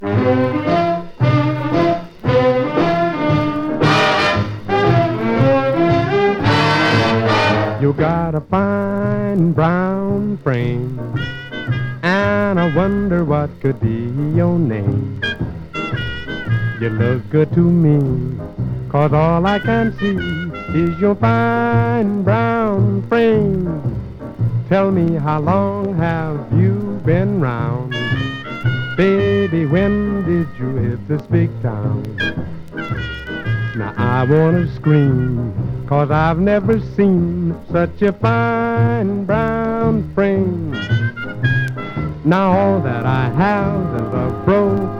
どれもギラギラとした豪快かつダンサブルなナンバーがたっぷりの構成が嬉しい1枚です。
Jazz, Blues, Jump Blues, Swing　USA　12inchレコード　33rpm　Mono